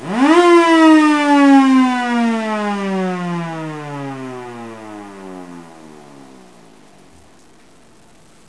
Nuclear Air Raid Siren
tbolt0001_no blower.wav